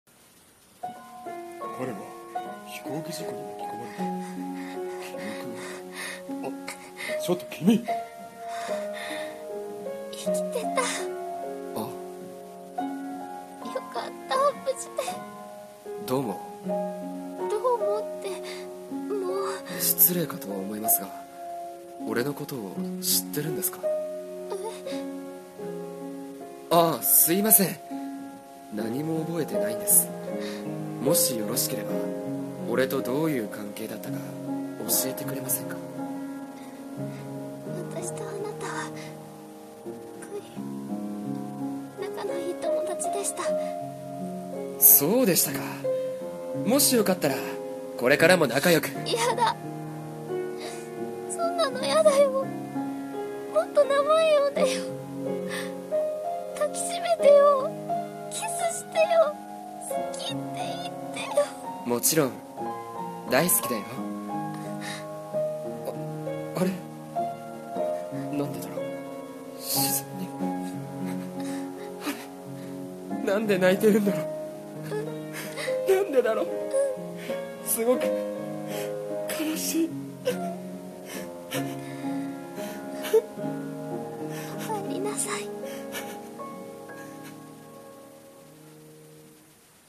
声劇(掛け合い声面接) 〜無事なのに..・後編〜